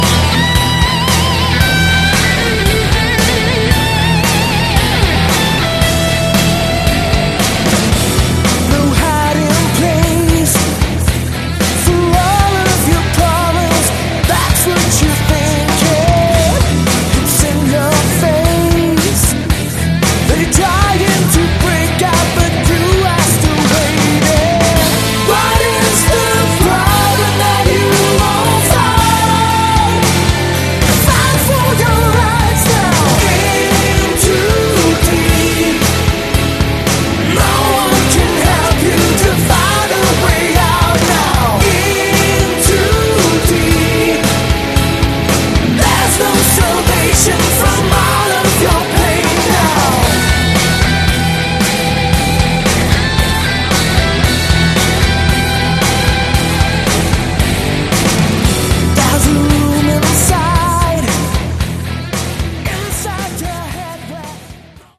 Category: AOR
Vocals
Guitar, Keyboards
Bass
Drums